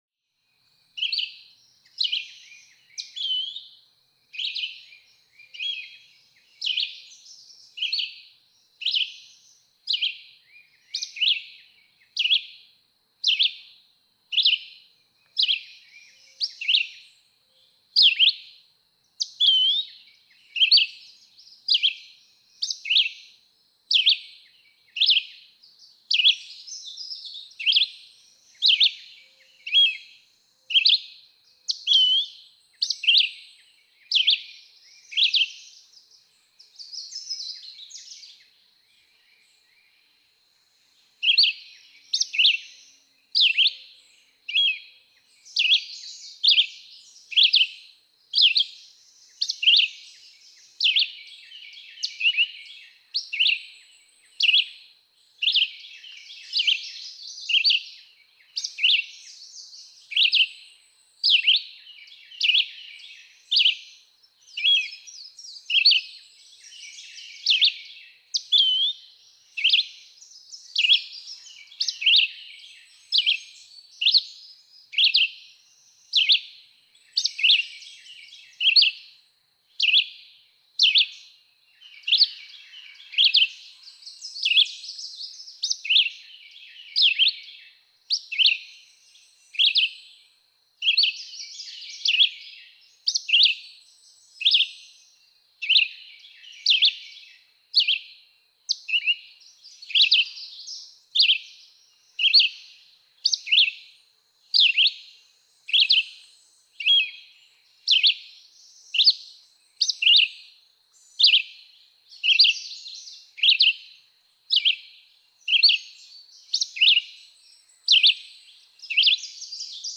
Red-eyed vireo
Subchapter: Improvised songs
♫157. Song, from Kentucky. May 10, 2012. Land Between the Lakes, Kentucky. (3:21).
157_Red-eyed_Vireo.mp3